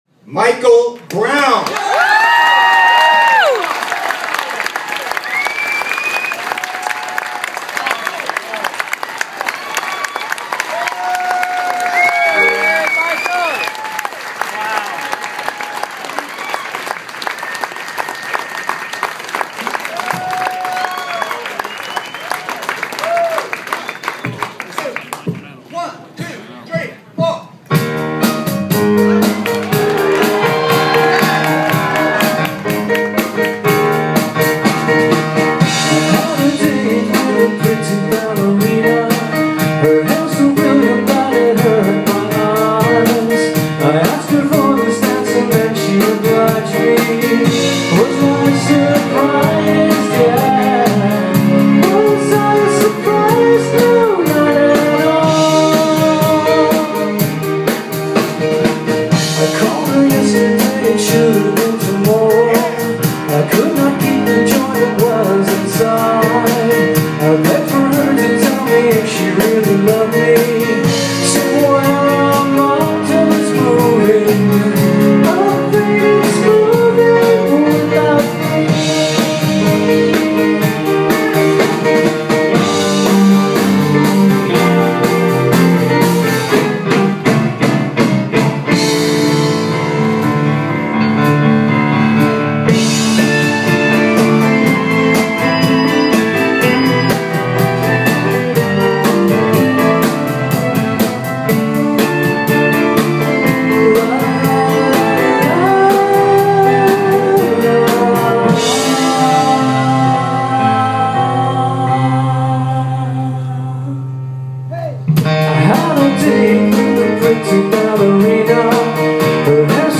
coined by the Press as Baroque Rock
Sadly, the sound isn’t as good as one would have hoped.